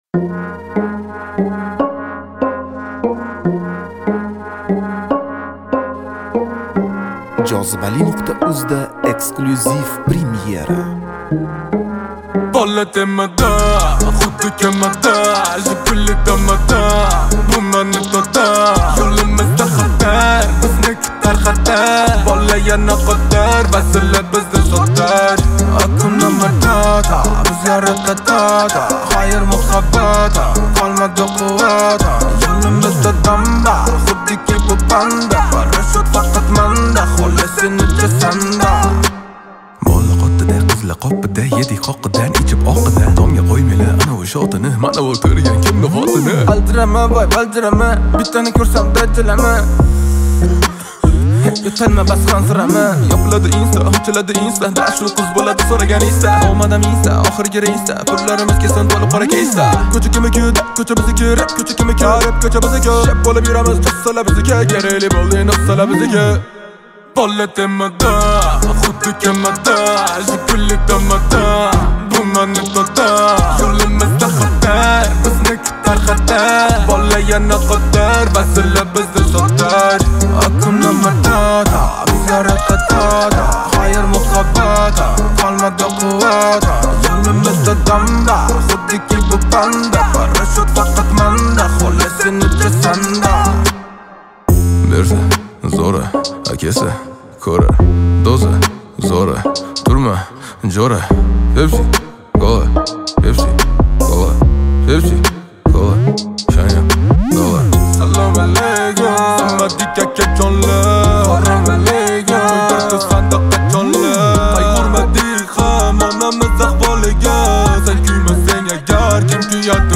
Узбекский рэп